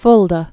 (fldə)